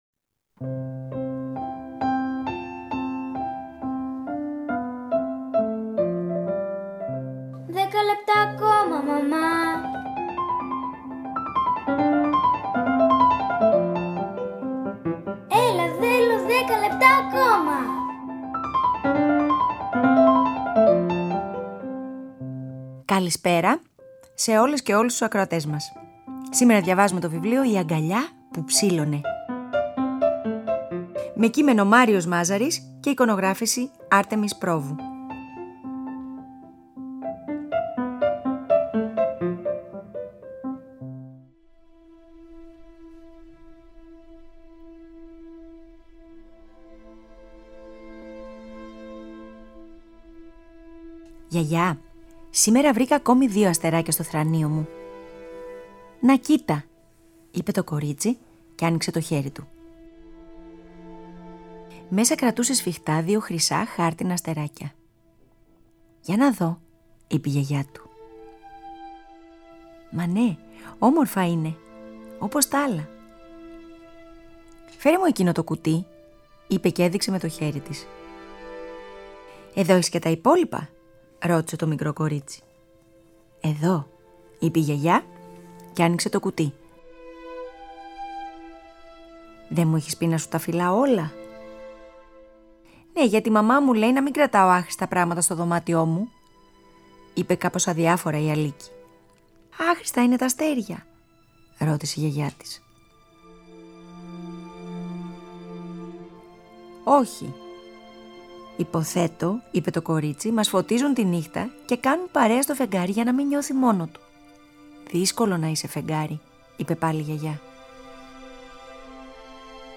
Αφήγηση-Μουσικές επιλογές